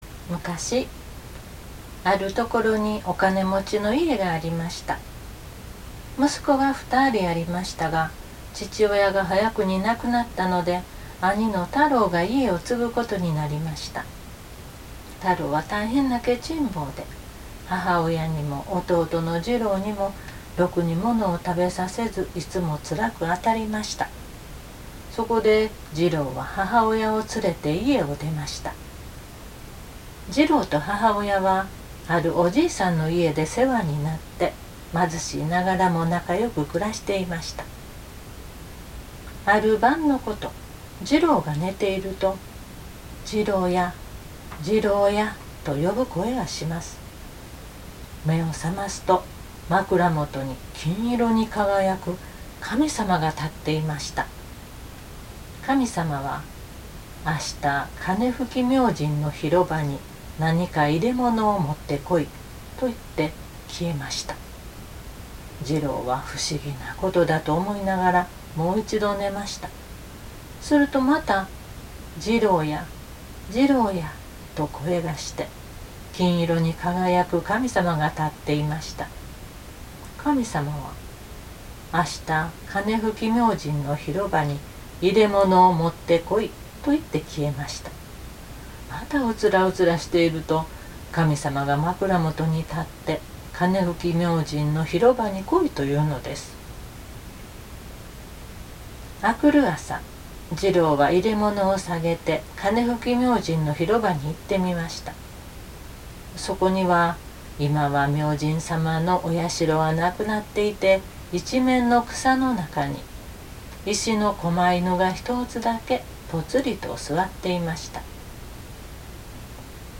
ストーリーテリング 語りの森 日本の昔話
日常語で語っています。